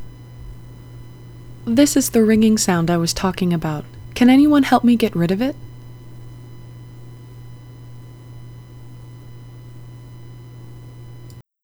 This is the one that originally called for 20 iterations of suppression to get rid of all the tones.